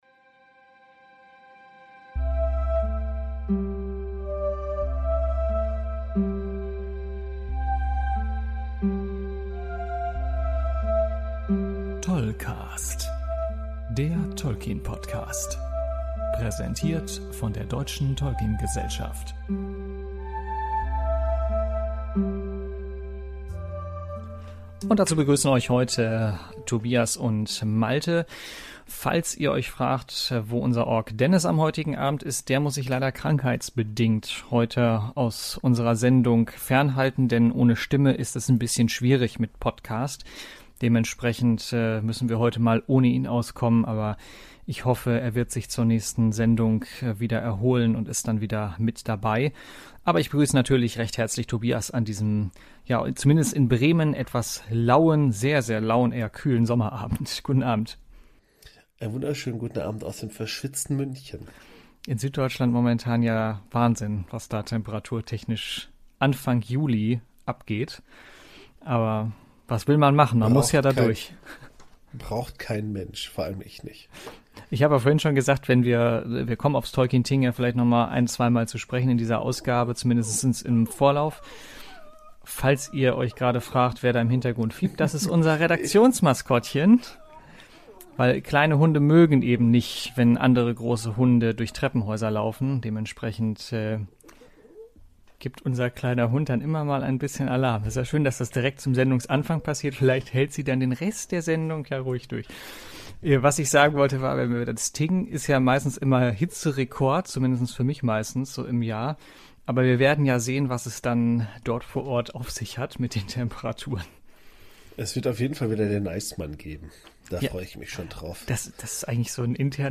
O-Töne vom Tolkien Tag
O-Tönde von der CCXP